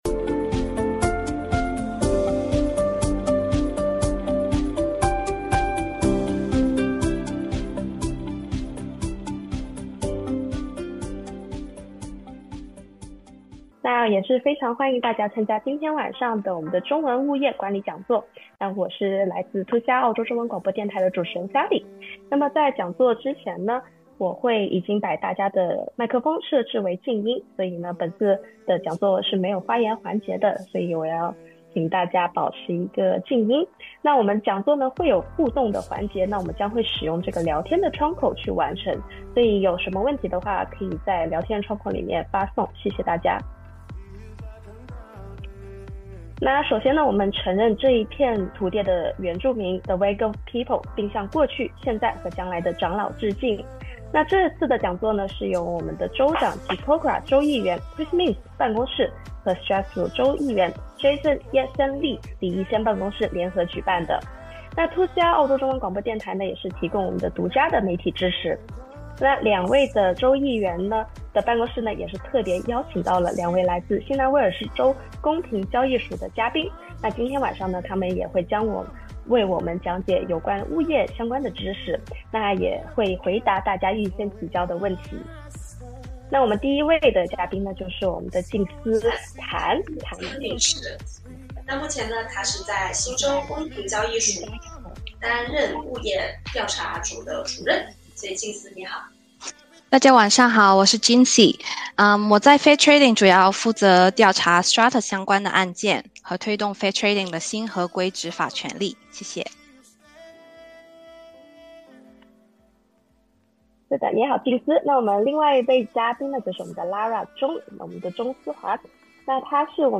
由新州州長柯民思（Chris Minns） 辦公室與 Strathfield 選區州議員李逸仙（Jason Yat-Sen Li）辦公室聯合主辦、新州公平交易署（NSW Fair Trading）支持的普通話物業管理講座，於 11 月 7 日晚在綫上 Zoom 舉辦，約百名華人居民踴躍參與，活動以全程中文講解，深入解析分契式物業（Strata）制度及新近法規改革，獲社區熱烈反響。
NSW Fair Trading 專員以普通話逐一解答，並強調各項責任劃分原則、申訴及調解程序，提醒業主妥善保存維修及溝通紀錄以保障權益。
Chinese-Language-Strata-Forum-Online-Version.mp3